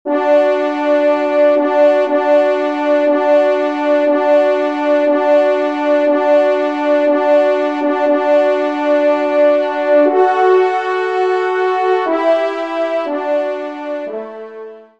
Genre :  Musique Religieuse pour Trois Trompes ou Cors
Pupitre 2°Trompe